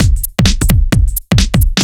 OTG_Kit 2_HeavySwing_130-C.wav